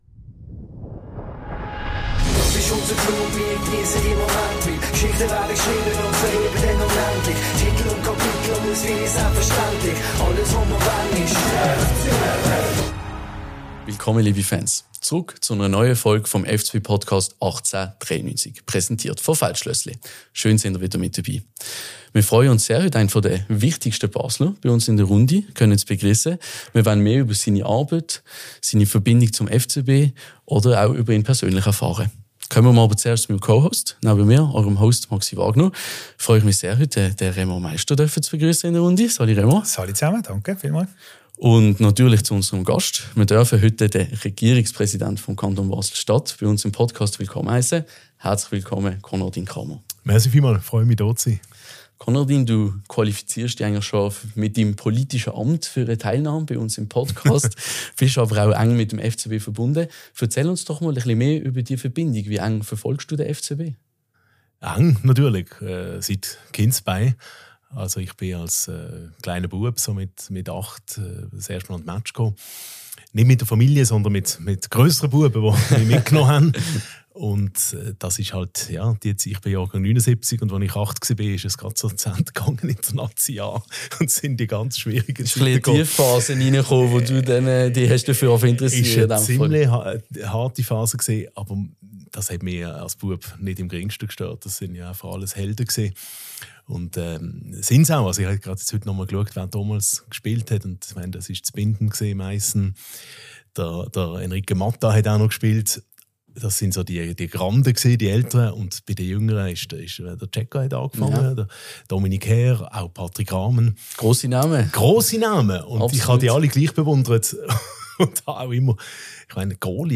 Ein spannendes Gespräch an der Schnittstelle von Fussball, Politik und Stadtleben!